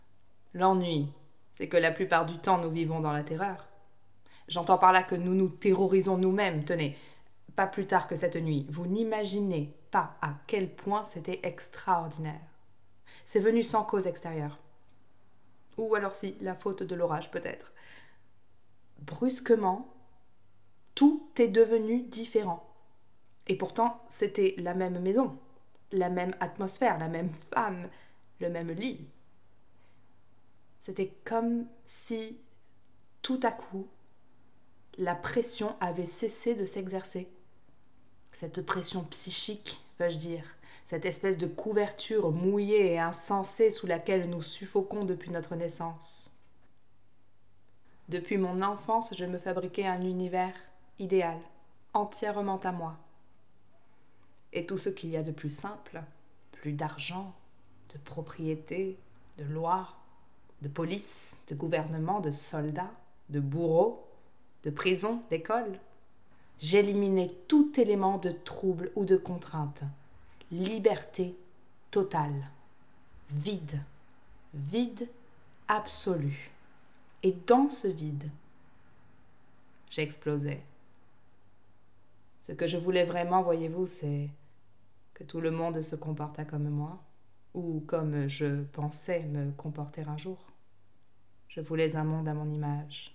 Voix Narration Roman Fr
20 - 45 ans - Mezzo-soprano